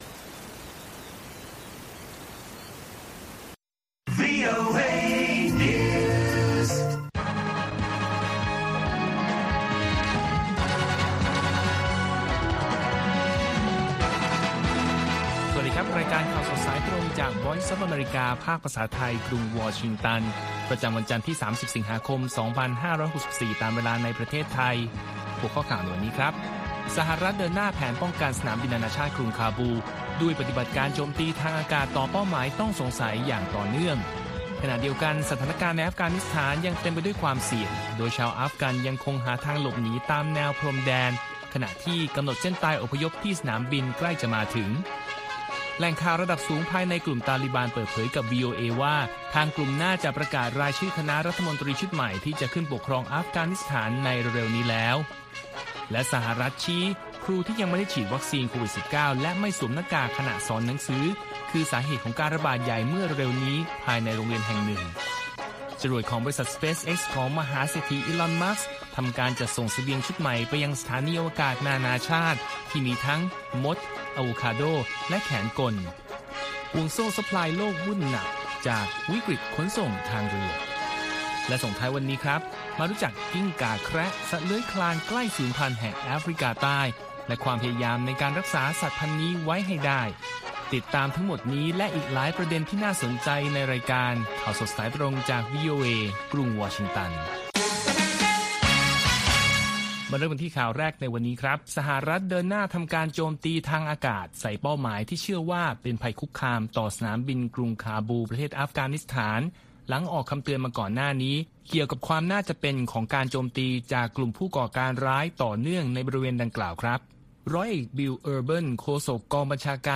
ข่าวสดสายตรงจากวีโอเอ ภาคภาษาไทย 8:30–9:00 น. ประจำวันจันทร์ที่ 30 สิงหาคม 2564